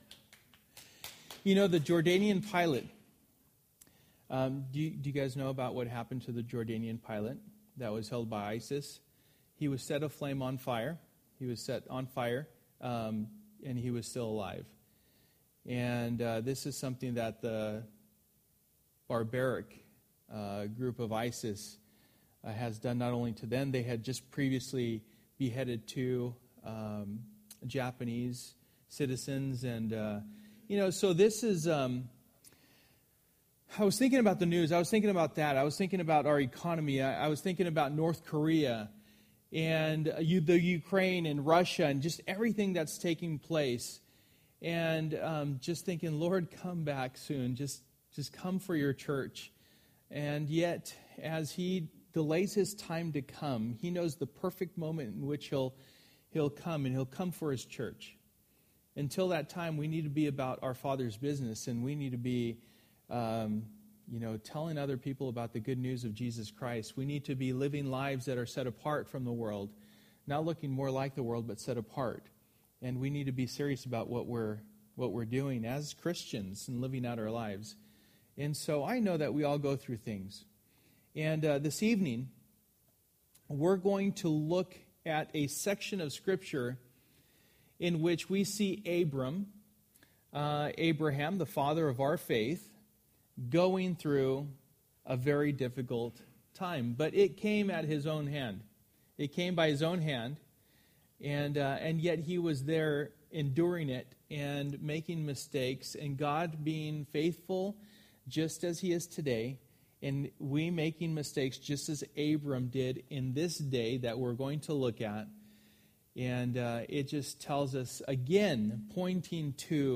Through the Bible Service: Wednesday Night %todo_render% « God or Counterfeit God.